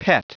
Prononciation du mot pet en anglais (fichier audio)
Prononciation du mot : pet